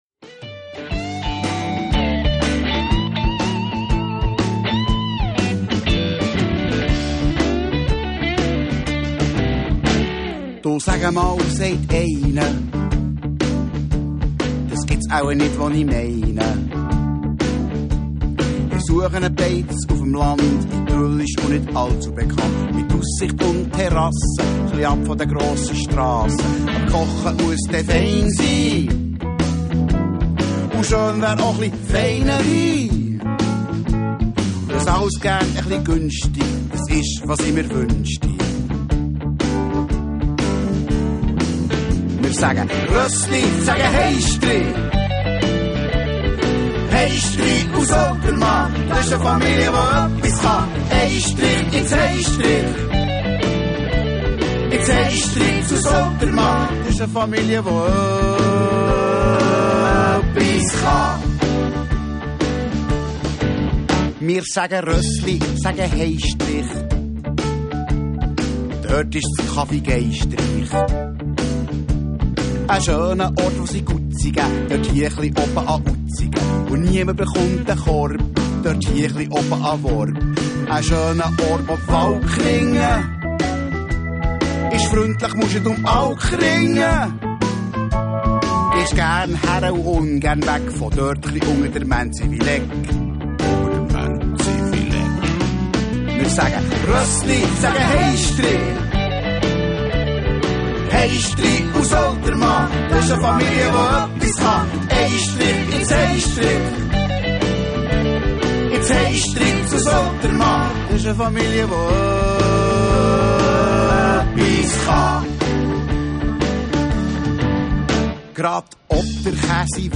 Gesang
Chor
Gitarre
Bass
Drums
Piano/Orgel